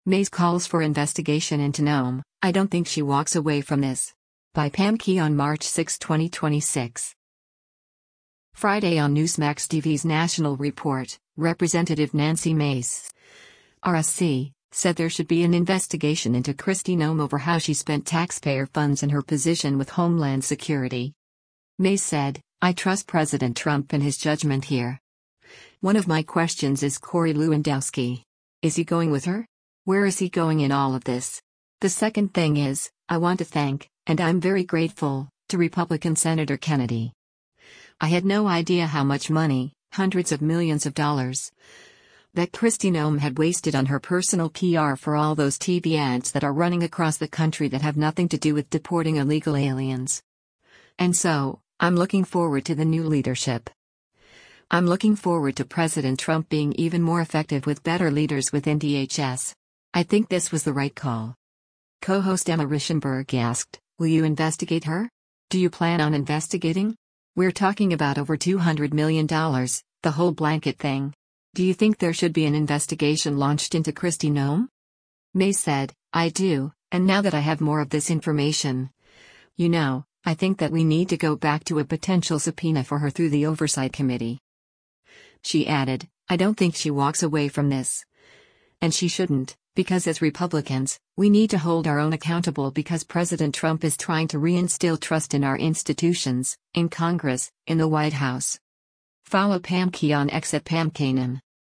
Friday on Newsmax TV’s “National Report,” Rep. Nancy Mace (R-SC) said there should be an investigation into Kristi Noem over how she spent taxpayer funds in her position with Homeland Security.